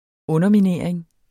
Udtale [ -miˌneˀɐ̯eŋ ]